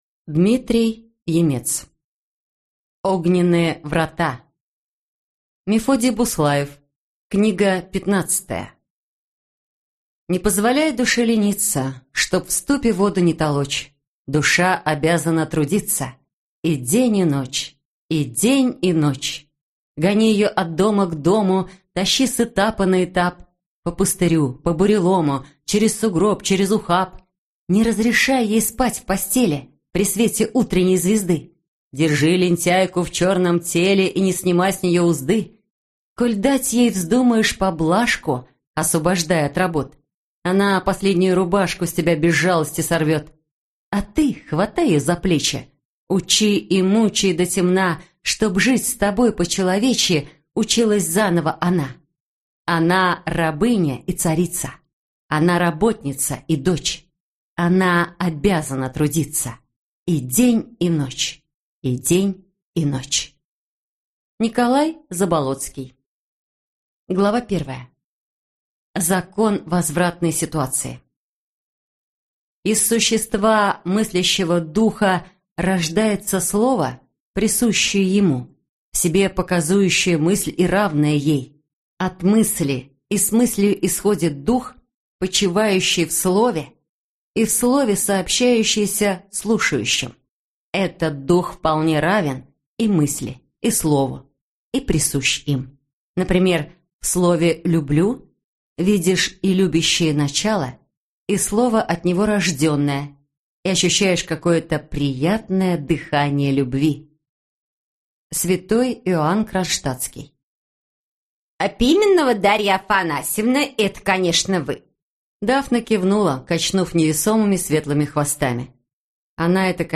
Аудиокнига Огненные врата | Библиотека аудиокниг